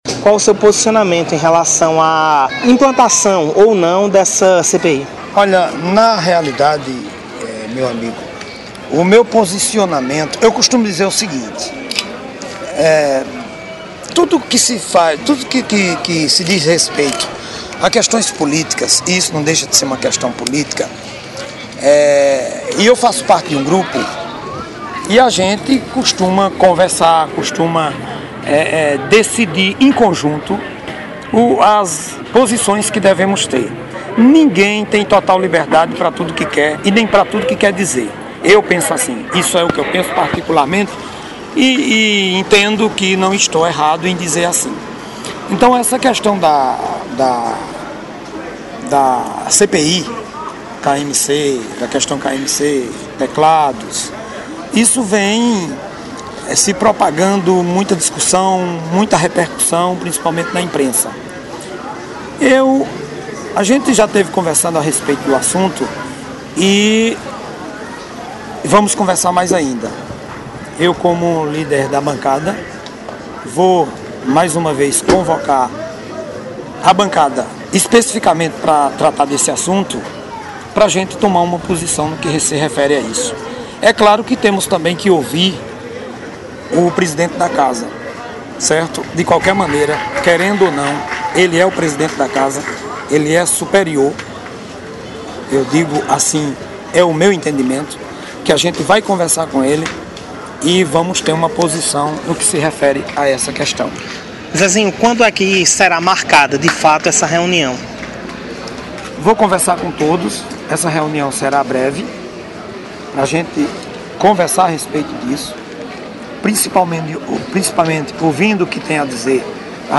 Em entrevista concedida ao blog, o vereador Zezin Buxin, que é líder da bancada de Situação, falou seu posicionamento sobre a polêmica em relação a não realização da CPI da KMC.